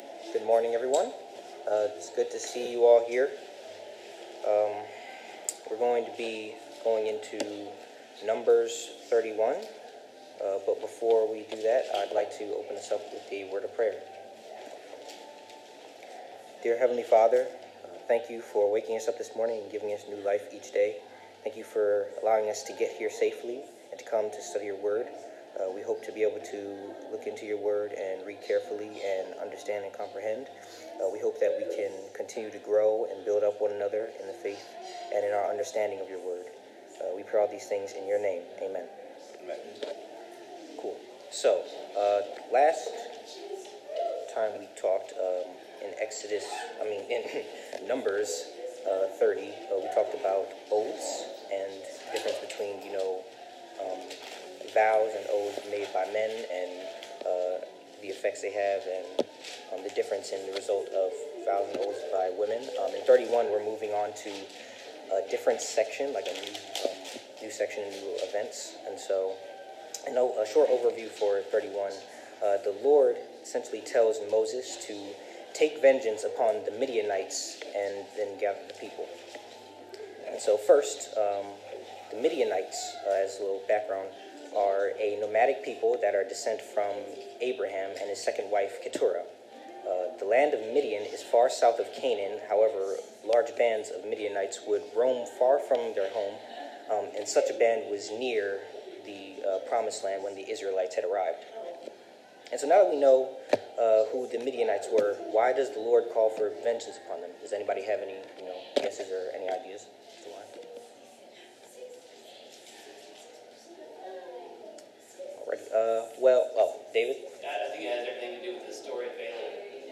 Bible Class: Numbers 31-33